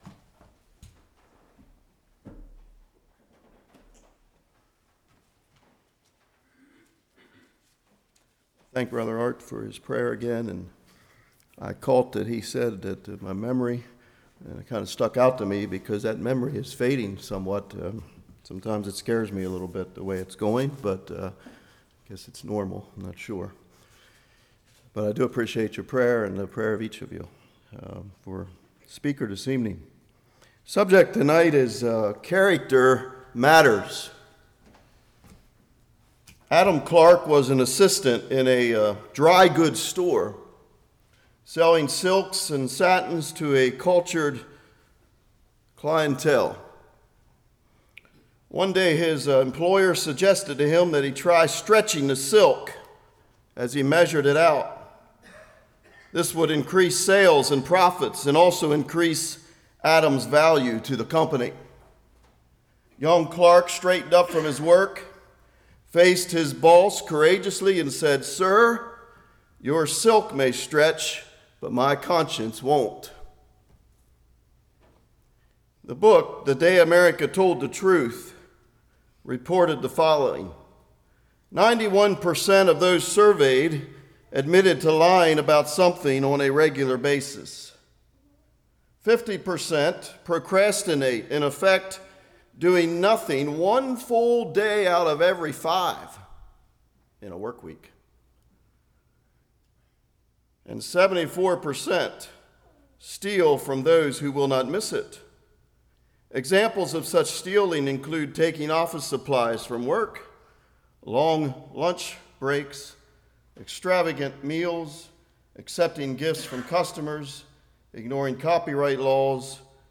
Genesis 39:1-23 Service Type: Evening It’s Not Fair Does Bring God’s Blessing?